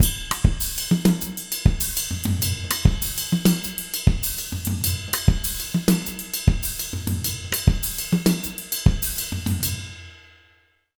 Index of /90_sSampleCDs/USB Soundscan vol.08 - Jazz Latin Drumloops [AKAI] 1CD/Partition E/03-100MOZAMB